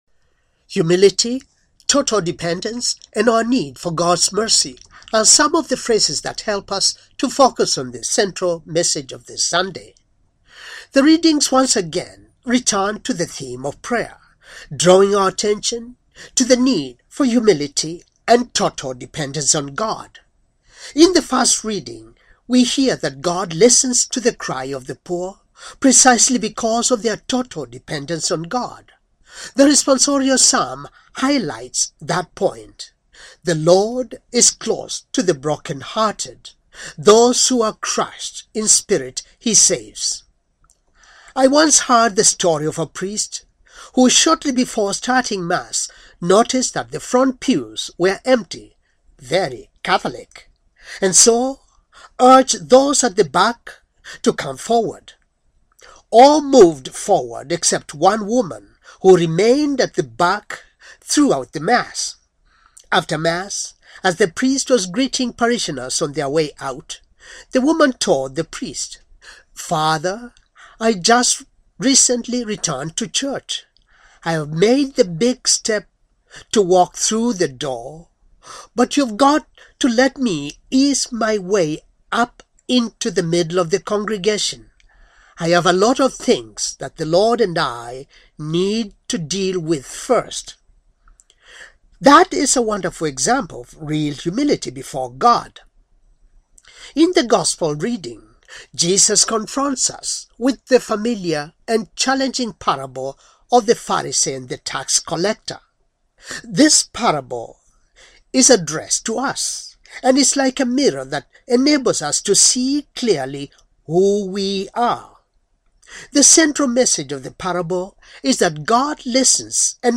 Homily, thirtieth, Sunday, ordinary, time, year c